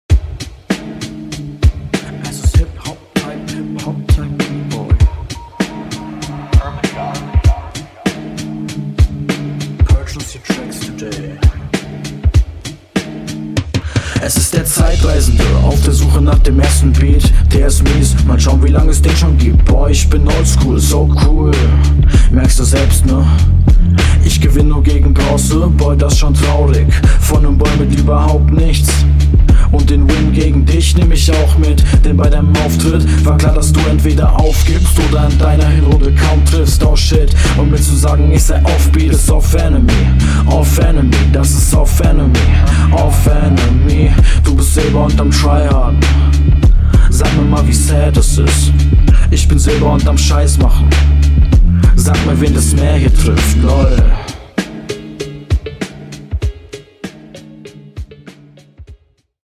Du schaffst es nicht, dass beim Hörer ein ähnlicher Hörgenuss aufkommt wie bei deinem Gegenüber. …
Flow: Super gut gesetze Pausen, ein paar mal die Lines sehr stylisch überzogen, aber an …